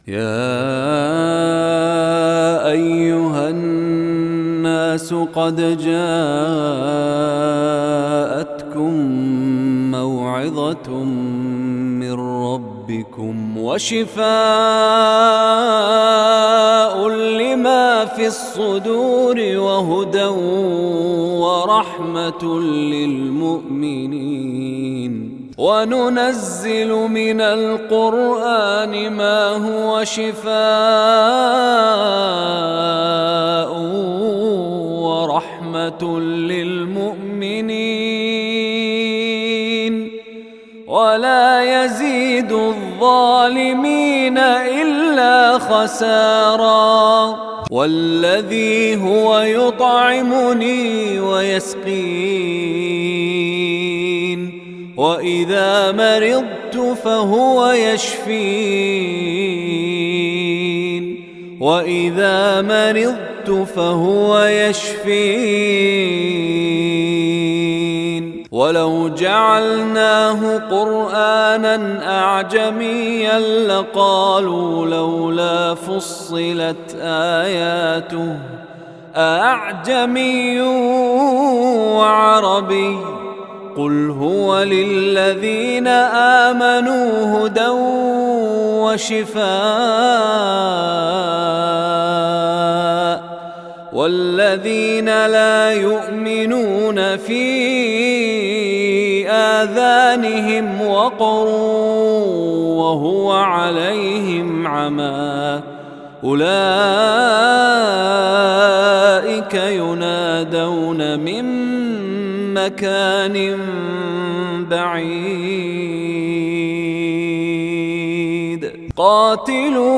রুকইয়াহ অডিও